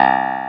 CLAVI6 C2.wav